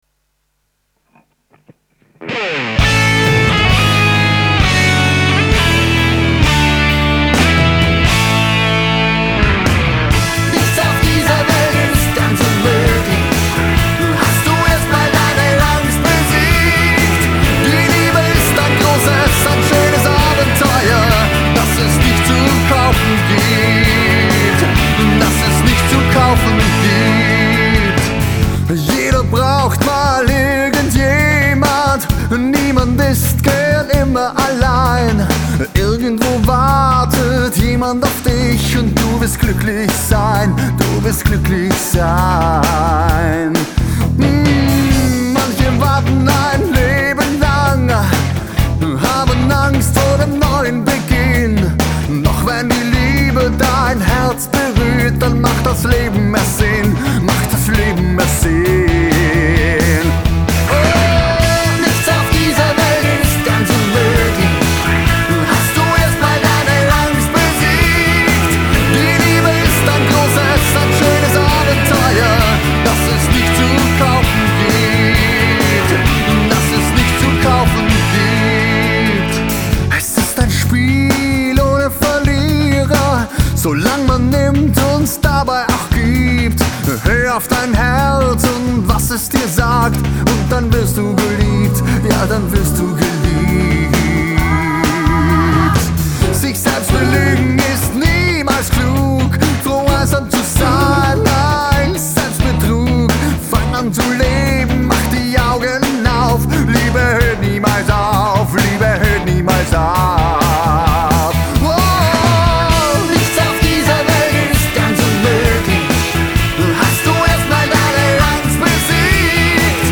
Nichts auf dieser Welt ist ganz unmöglich (STUDIO MIX 1).mp3